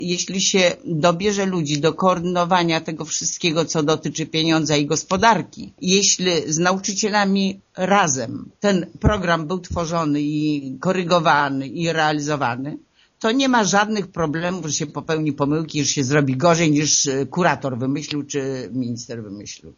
Jej zdaniem skuteczna realizacja planu oświatowego nie byłaby możliwa bez współpracy ze strony nauczycieli i dyrektorów placówek: